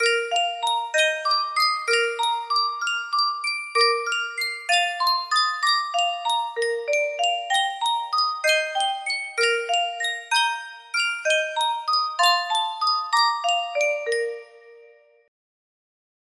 Sankyo Music Box - Now is the Hour F8 music box melody
Full range 60